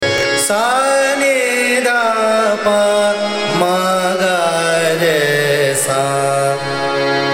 Raga
AvarohaS’ n d P M G R S
Bhairavi (Avaroha)